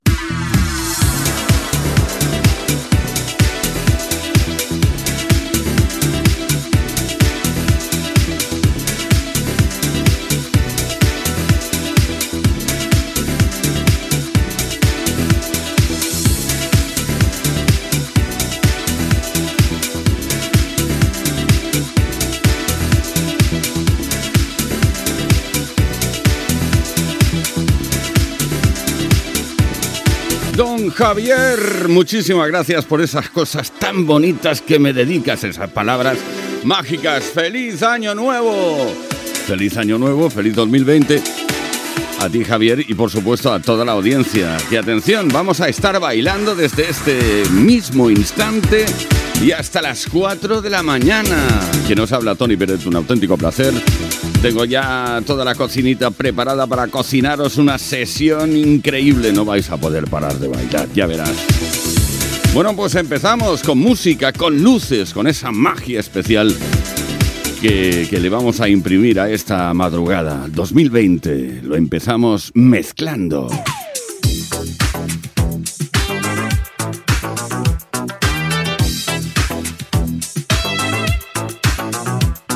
Inici del programa especial de cap d'any amb el desig de feliç any 2020 i un tema musical
Musical